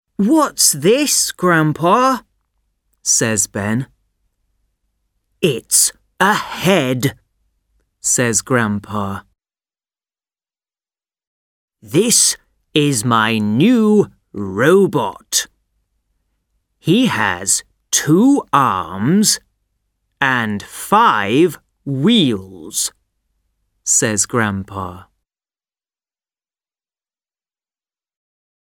Track 2 Hello, Clunk British English.mp3